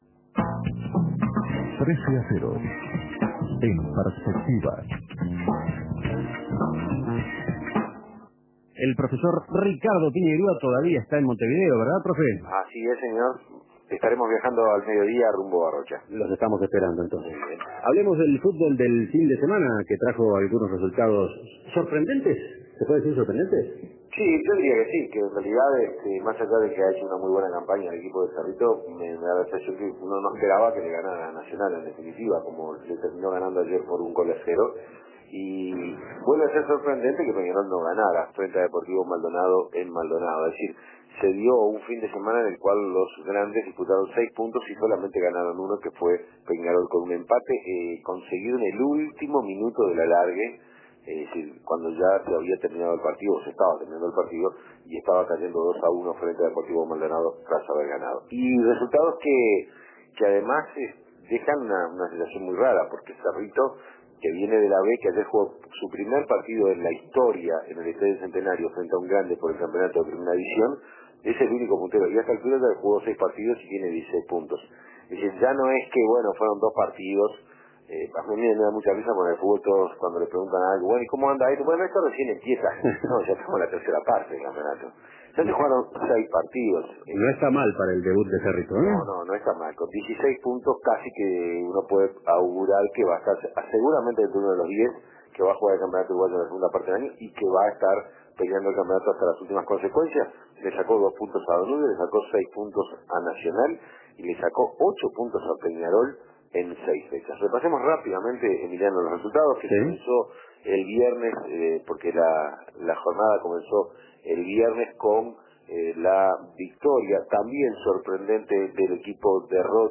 Entrevista con el jugador del Deportivo Maldonado.